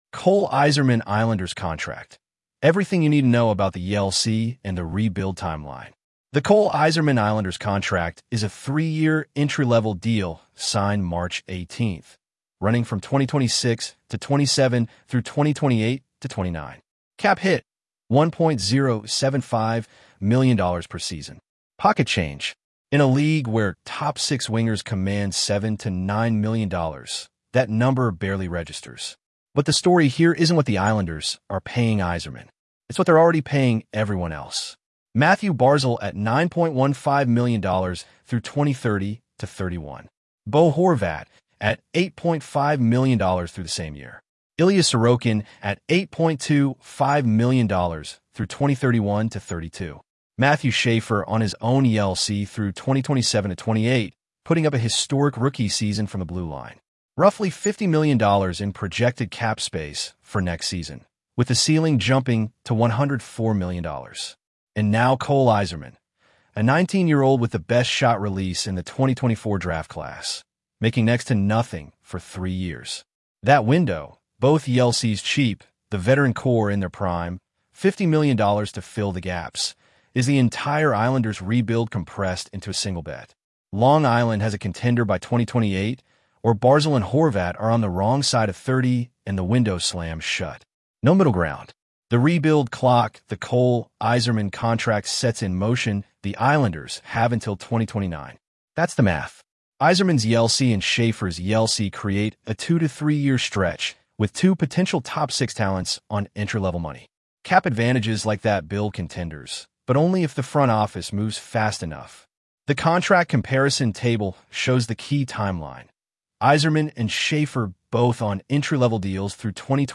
Listen to the audio version of Cole Eiserman Islanders Contract: The 3-Year Rebuild Window